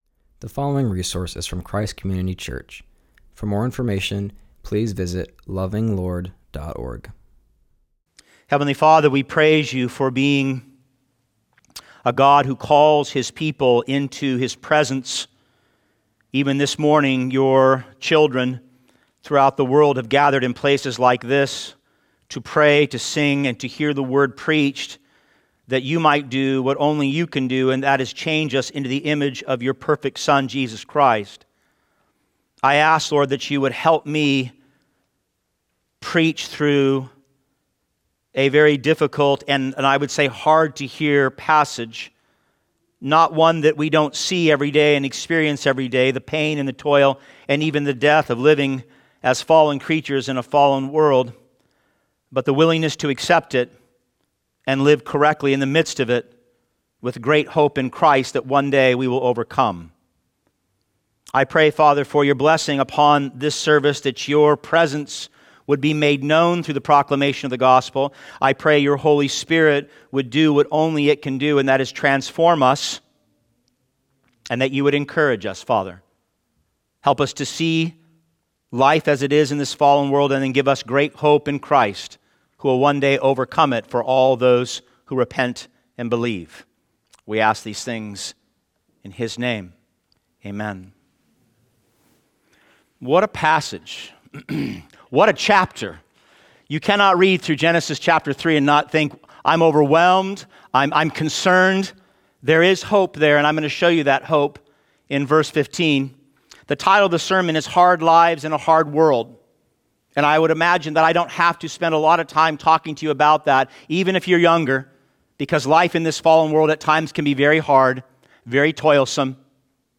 preaches from Genesis 3:14-19